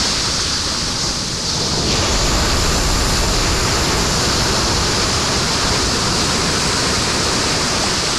Wave1.ogg